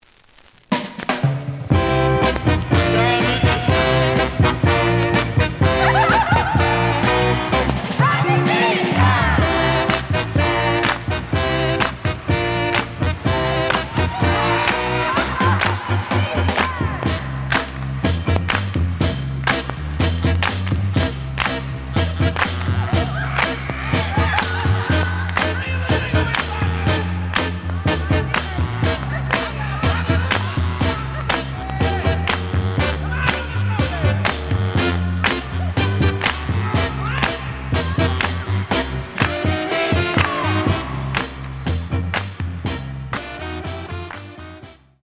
The audio sample is recorded from the actual item.
●Genre: Soul, 60's Soul
Some click noise on beginning of A side due to scratches.